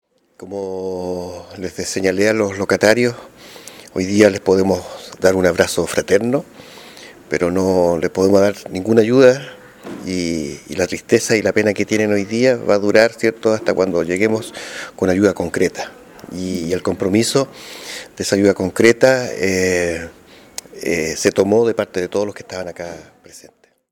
Pedro Valencia, presidente de la comisión de Fomento Productivo del CORE, indicó por su parte, que la comisión acordó aprobar la voluntad política de financiamiento para cualquier iniciativa que vaya en favor de avanzar en solucionar los efectos del devastador incendio ocurrido en la Plaza de Abastos de Illapel.